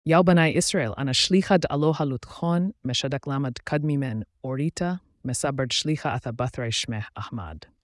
یک نسخه شبیه‌سازی‌شده را (بر اساس TTS)